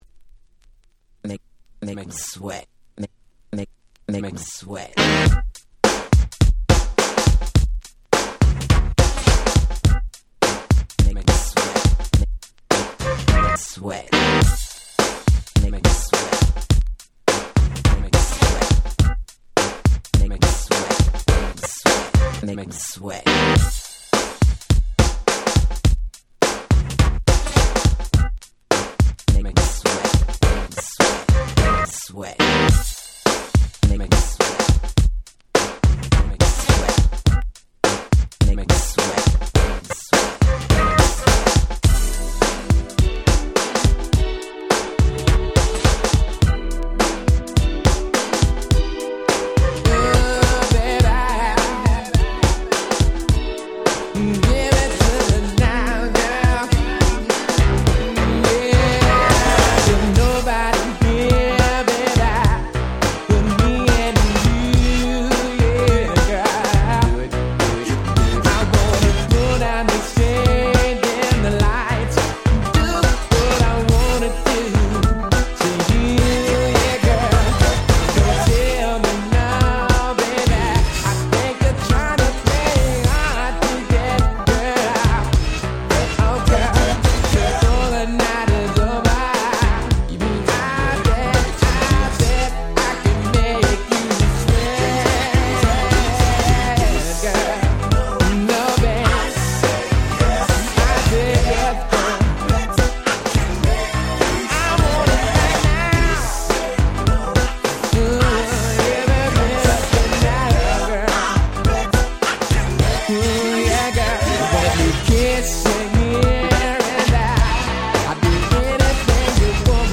90' Super Hit R&B / New Jack Swing !!